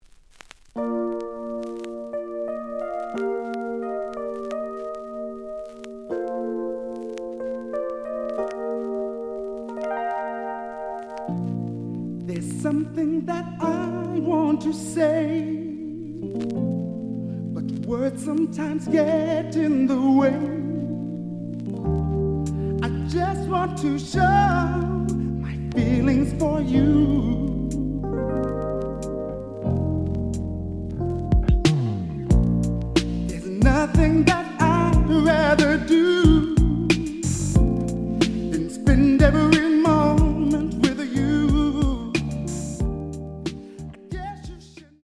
The audio sample is recorded from the actual item.
●Genre: Soul, 80's / 90's Soul
Slight noise on beginning of A side, but almost good.